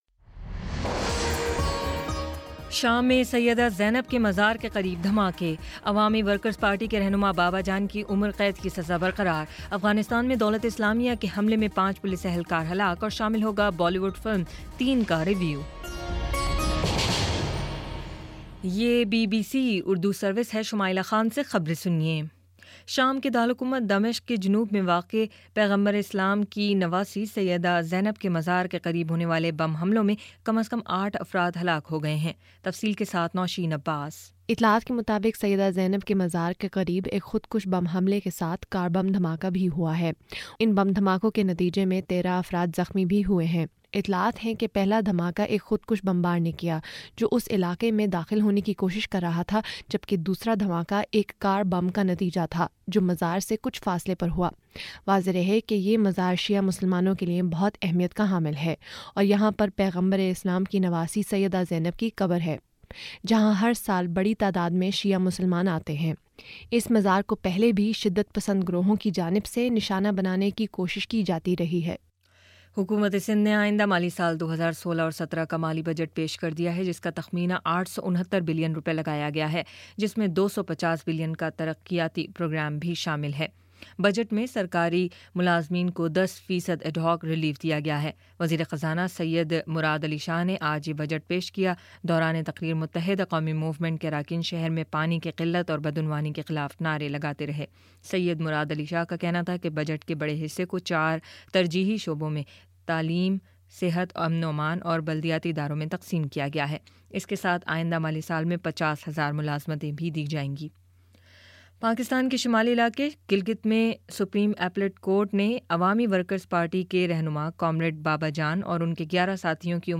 جون 11 : شام چھ بجے کا نیوز بُلیٹن